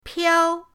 piao1.mp3